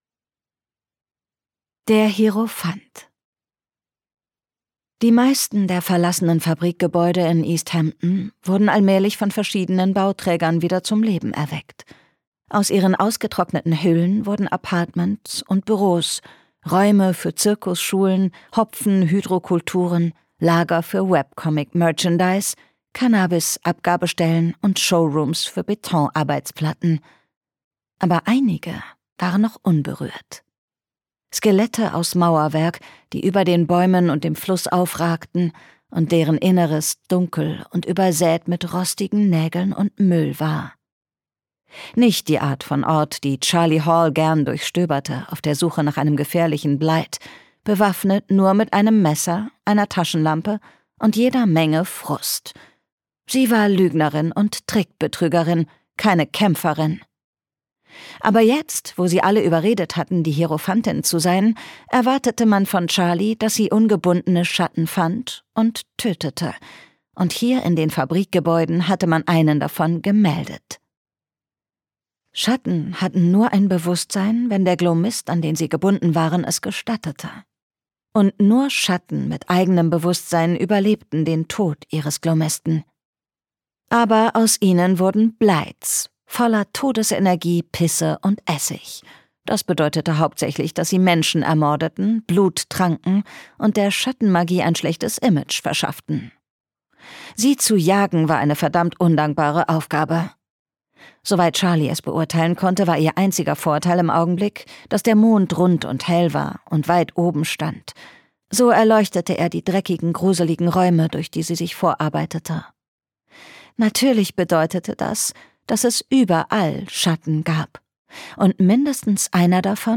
Gekürzt Autorisierte, d.h. von Autor:innen und / oder Verlagen freigegebene, bearbeitete Fassung.
Zur Sprecherin